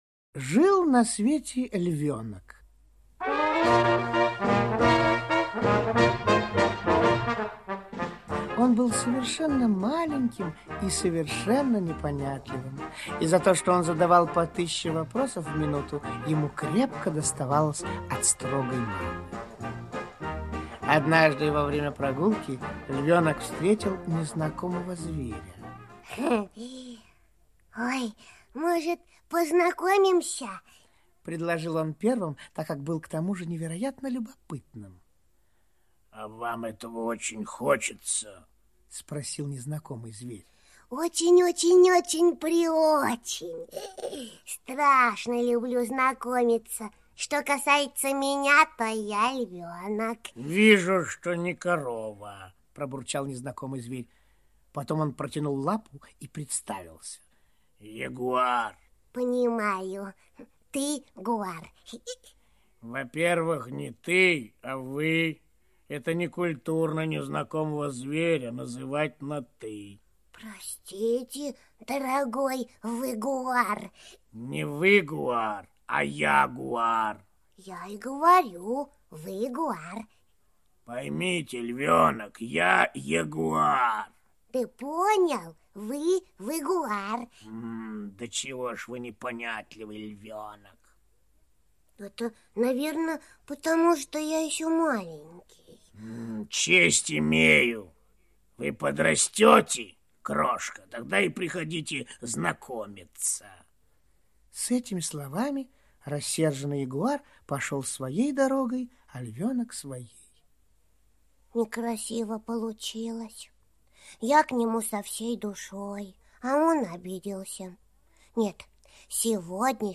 Аудиосказка «Непонятливый львенок»